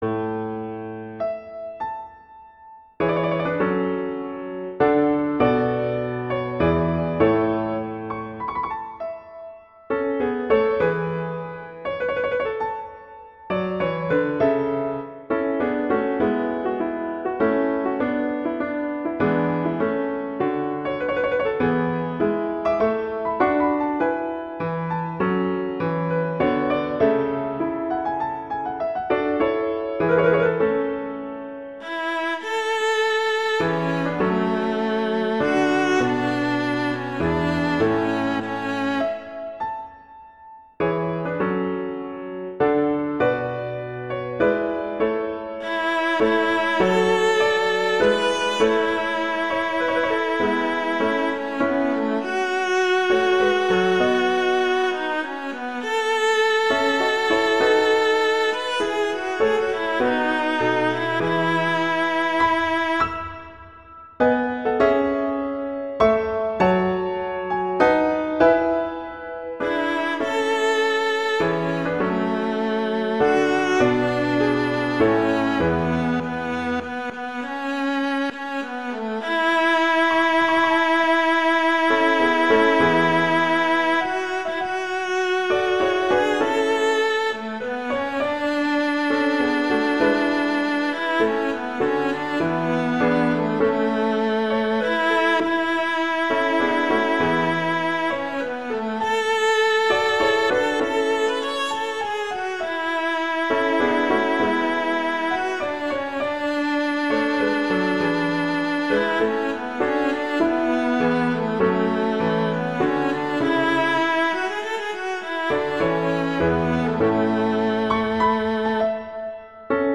arrangements for viola and piano
traditional, easter, holiday, children, french